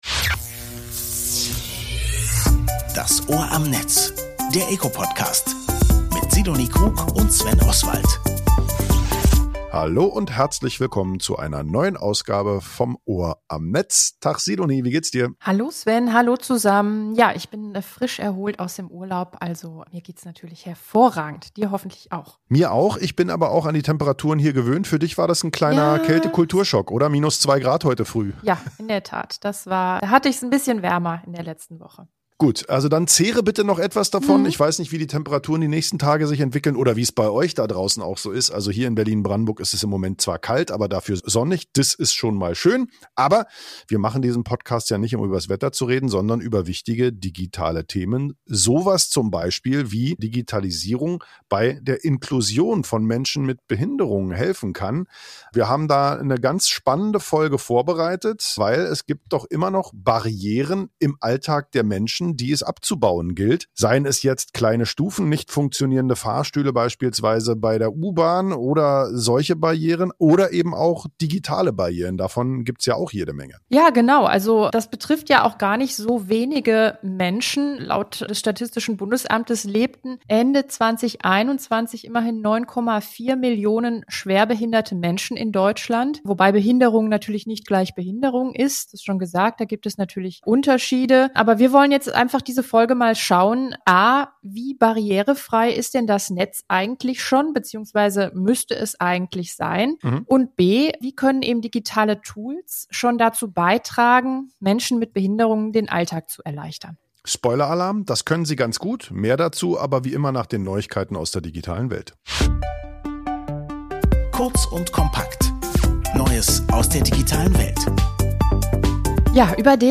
Jürgen Dusel , Beauftragter der Bundesregierung für die Belange von Menschen mit Behinderungen, erläutert im Interview, warum die Barrierefreiheit im digitalen Raum für ihn ein wichtiges Anliegen ist und wie der EAA dazu beiträgt, mehr Teilhabe im Netz zu schaffen.